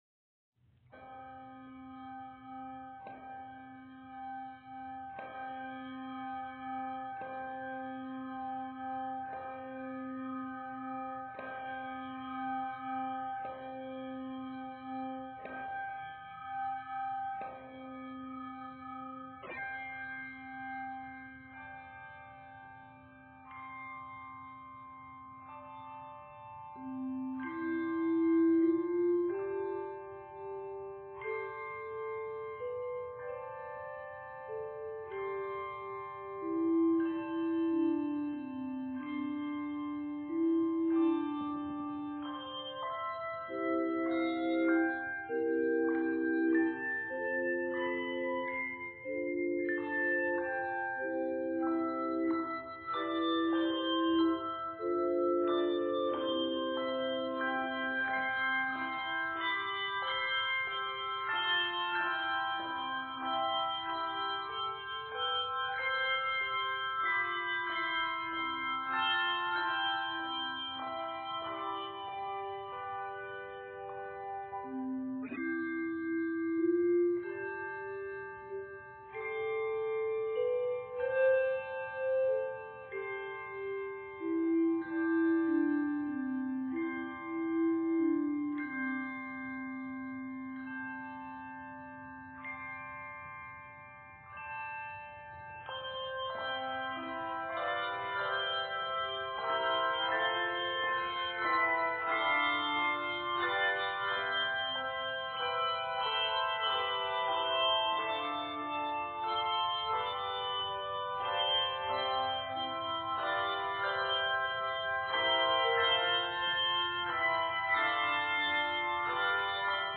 This version gives a good workout on soft dynamics.